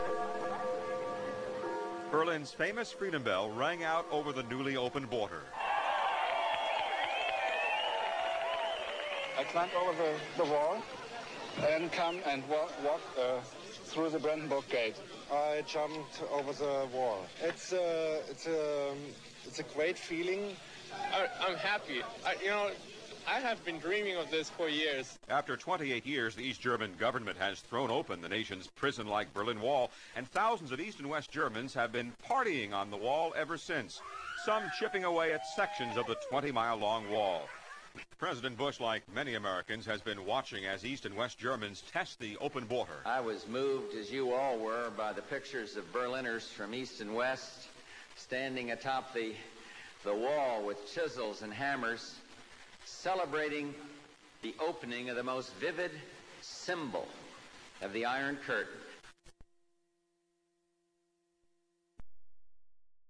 Hear the news of The Berlin wall crumbling.